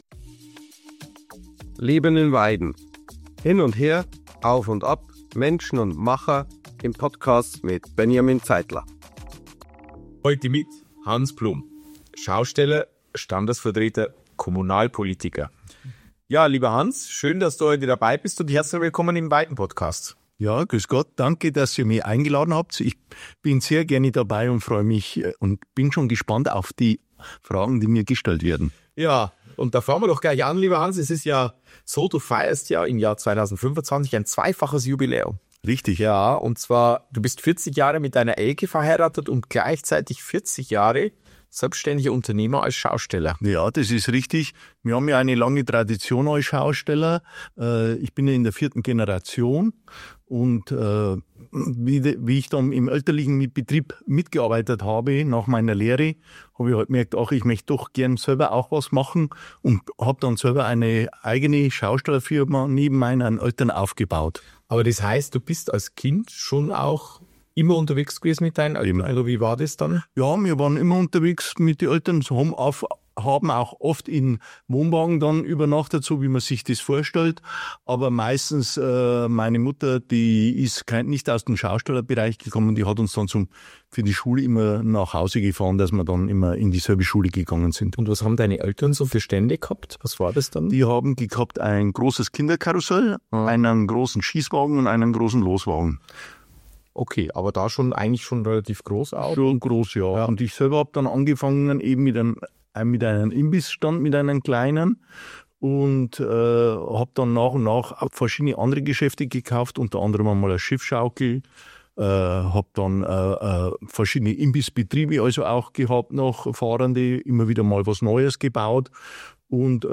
🎙 Folge #51 – Zwischen Rummel und Rathaus: Hans Blum über Familie, Volksfest und Weidens Zukunft In dieser Folge ist Hans Blum zu Gast – CSU-Stadtrat seit über 20 Jahren, stellvertretender Fraktionsvorsitzender, IHK-Mitglied und engagierter Mitgestalter von Volksfest und Christkindlmarkt. Ein Gespräch mit einem, der tief in der Geschichte und dem öffentlichen Leben Weidens verwurzelt ist. Wir sprechen über seine Herkunft aus einer traditionsreichen Schaustellerfamilie, die seit 1946 das Weidener Volksfest prägt, über das Kinderkarussell seiner Jugend – und darüber, wie sich sein berufliches Engagement im Laufe der Jahre verändert hat.